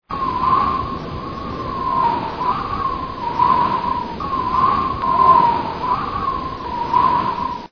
winterwind.mp3